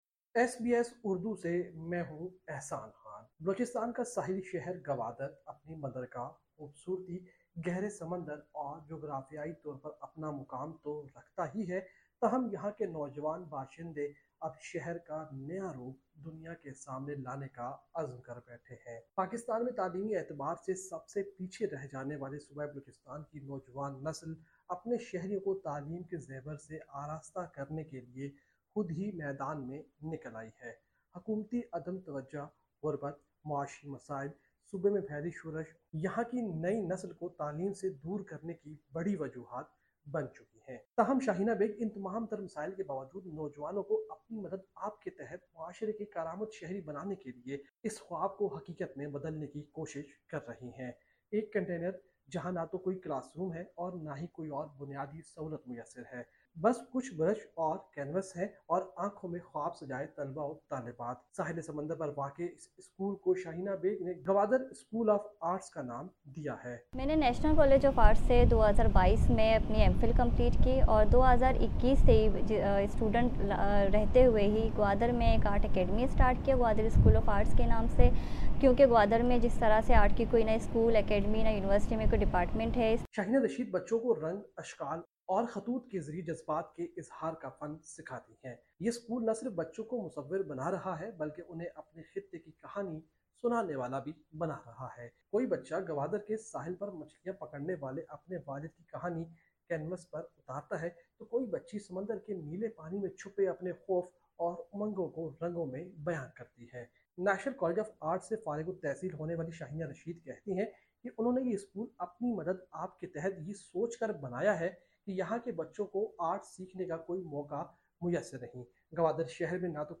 پاکستان رپورٹ: گوادراسکول آف آرٹس کا قیام اور کراچی میں 5 منزلہ عمارت منہدم